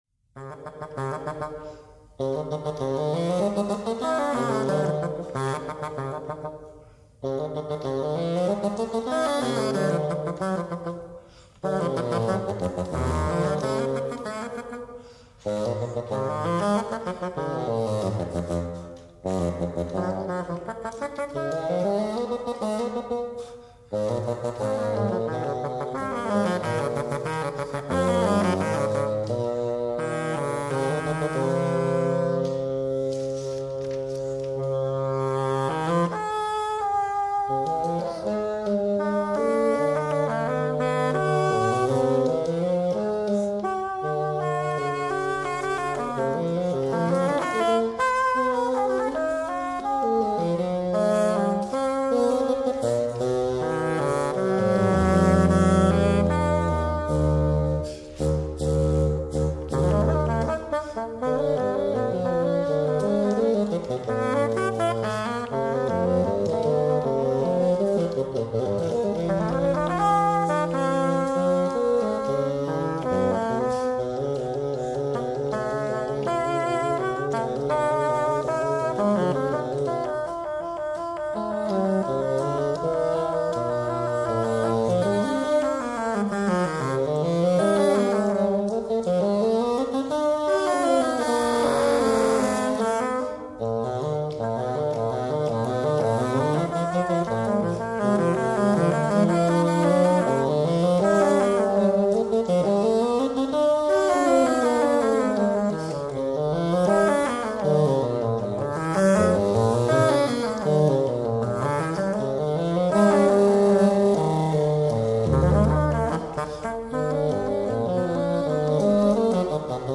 Stücke für 2 Fagotte
Ziemlich rasch  (2'46" - 2,6MB)